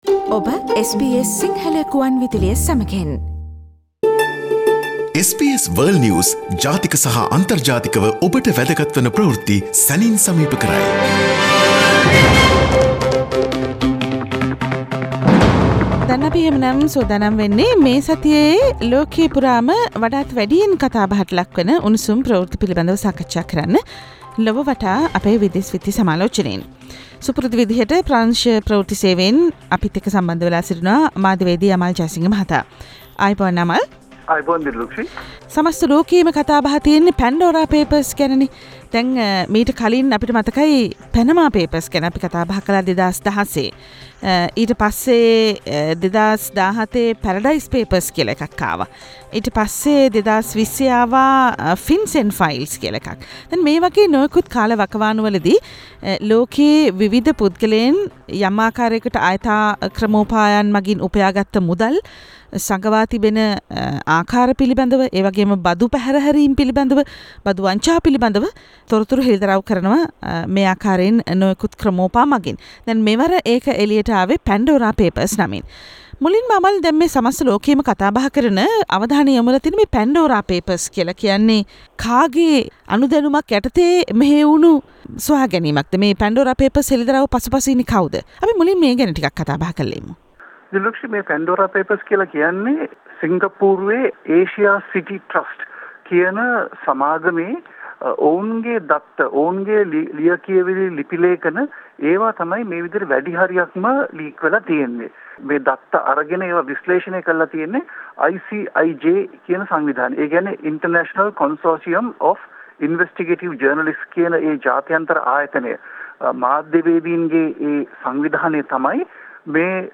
Weekly most prominent world news highlights from SBS Sinhala Radio